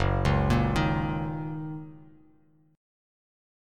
Gb6 Chord
Listen to Gb6 strummed